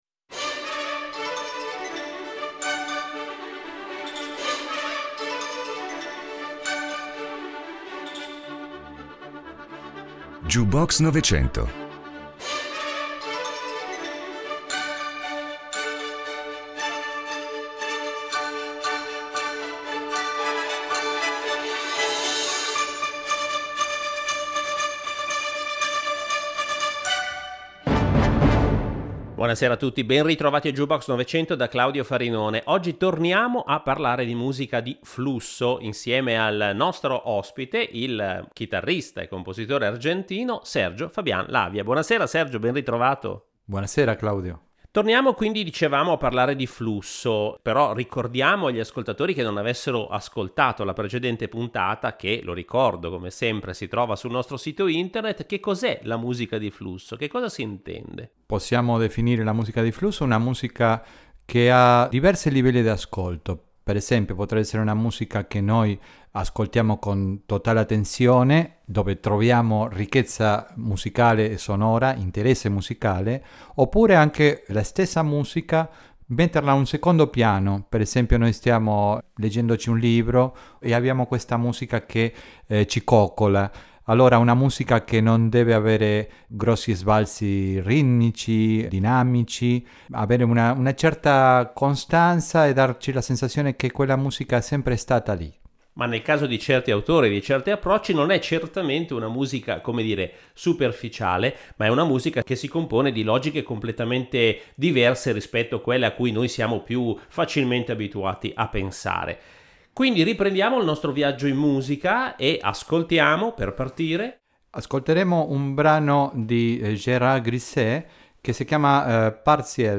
Linguaggi musicali che, se ascoltati con attenzione, possono essere interessanti e, allo stesso tempo, se non vengono portati in primo piano, possono comunque offrirci una certa ambientazione sonora che invita alla riflessione, al piacere e, perché no, al relax. Si parlerà di tecniche di composizione e improvvisazione, nuove tecnologie applicate alla musica e della nostra cultura mediale, che vede il flusso e la sua estetica come un tema sempre più importante e dominante nelle produzioni artistiche contemporanee.